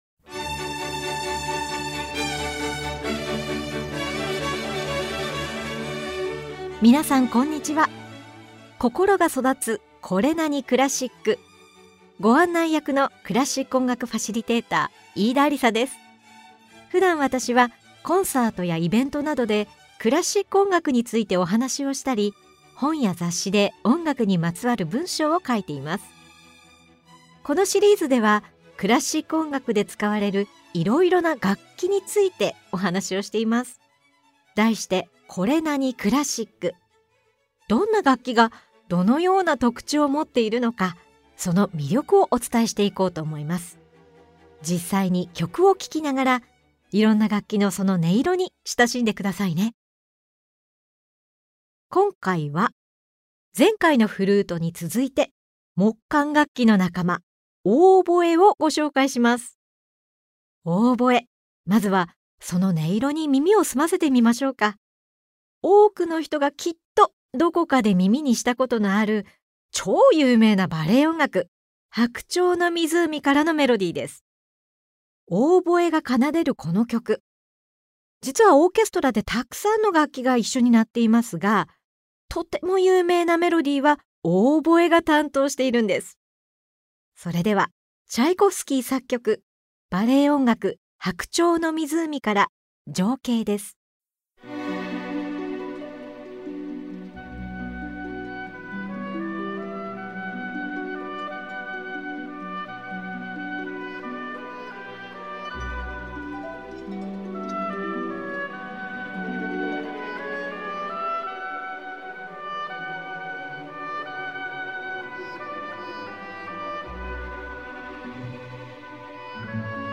このシリーズでは、どんな楽器がどのような特徴をもっているのか、その魅力をお伝えしていきます。実際に曲を聴きながら、いろんな楽器とその音色に親しんでください。
Vol.6では、繊細で哀愁を帯びた音から明るく力強い音まで幅広く表現できる木管楽器「オーボエ」に注目！冒頭のオーボエのメロディーがあまりにも有名な「白鳥の湖」、オーボエとオーケストラのための協奏曲を数多く残した作曲家アルビノーニの曲、オーボエと弦楽器の組み合わせによるアンサンブルが見事なモーツァルトの曲などを紹介しながら、オーボエのさまざまな音色とその魅力を紹介します！
[オーディオブック]